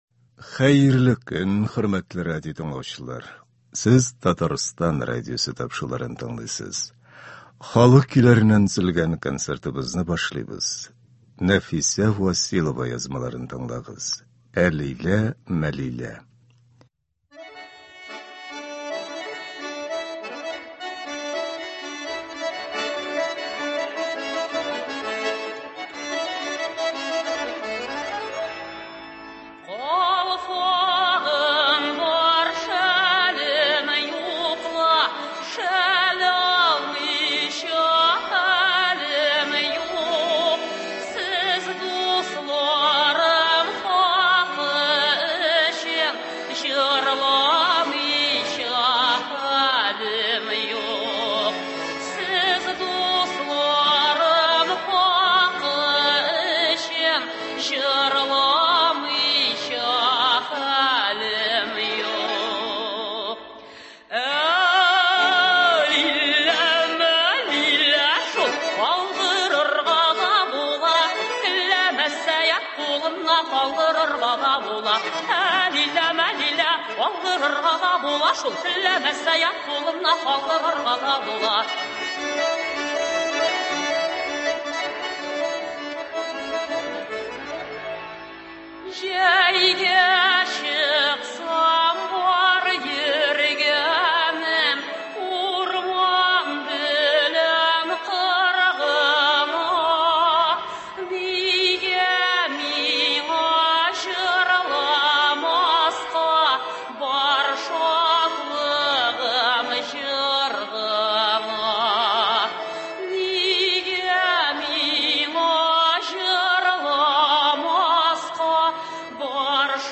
Татар халык көйләре (08.10.22)
Бүген без сезнең игътибарга радио фондында сакланган җырлардан төзелгән концерт тыңларга тәкъдим итәбез.